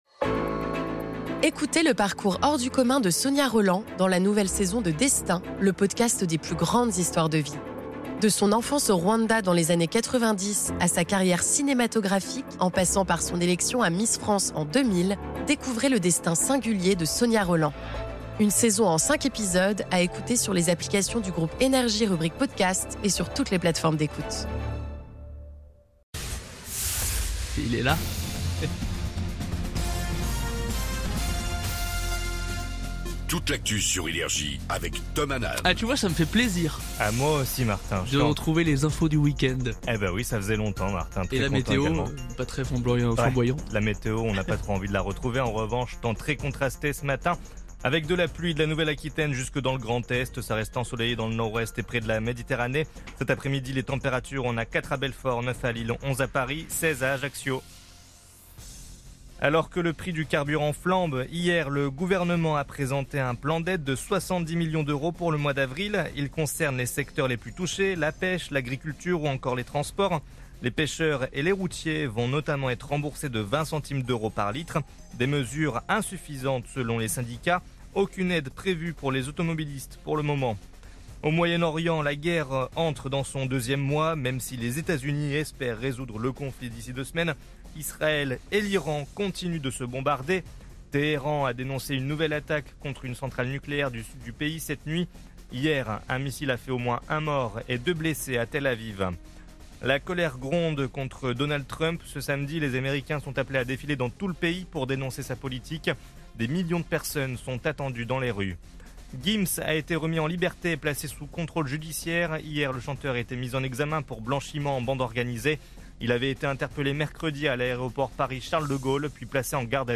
Réécoutez vos INFOS, METEO et TRAFIC de NRJ du samedi 28 mars 2026 à 06h00